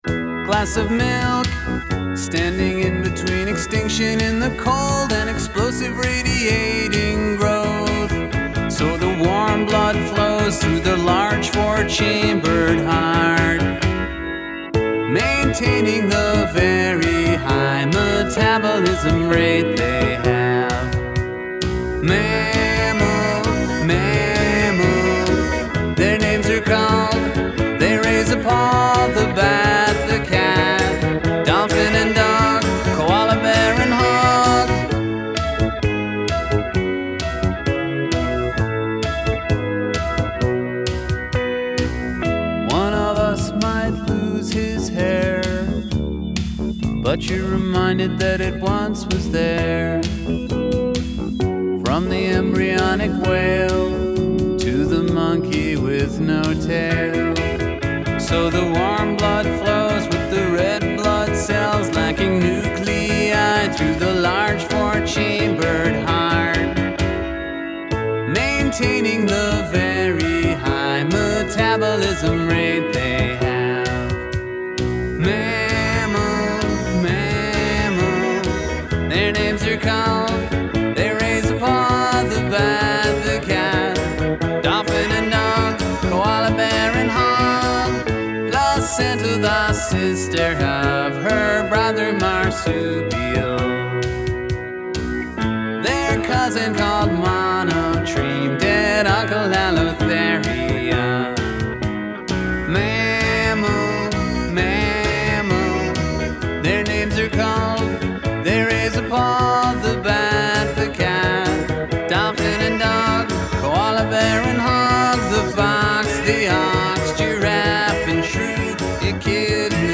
melancholy song